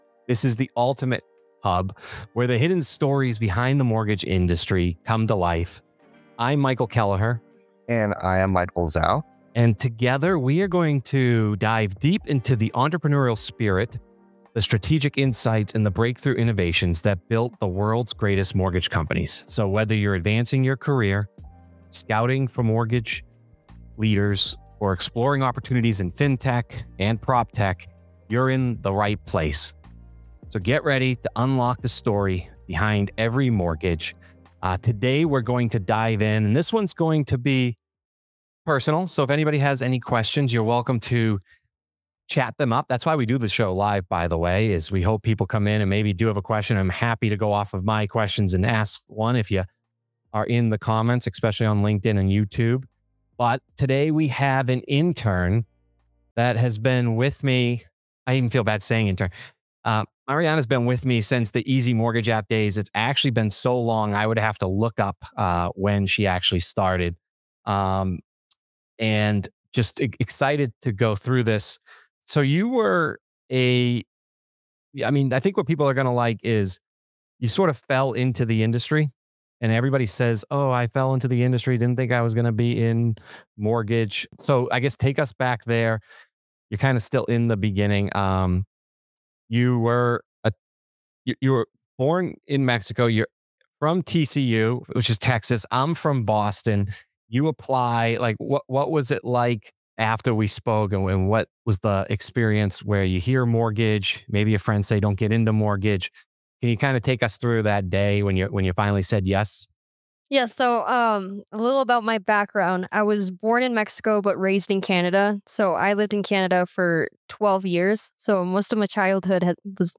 In this inspiring and refreshingly candid conversation